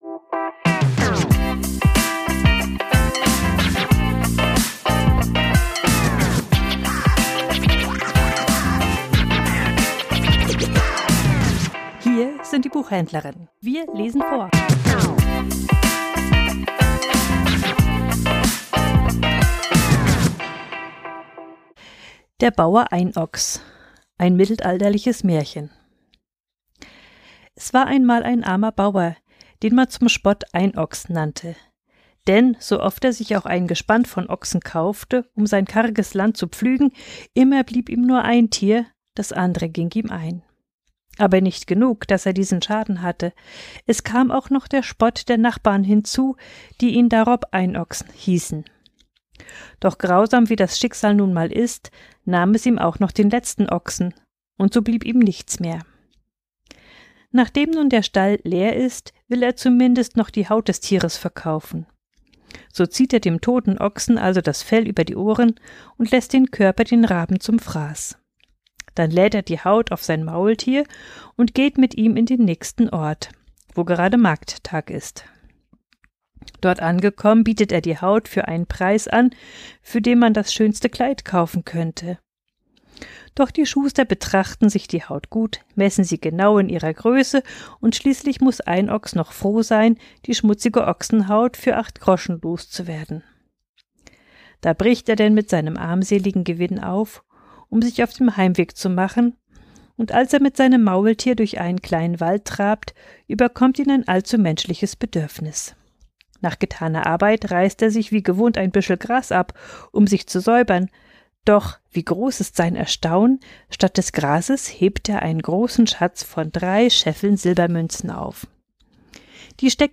Vorgelesen: Der Bauer Einochs ~ Die Buchhändlerinnen Podcast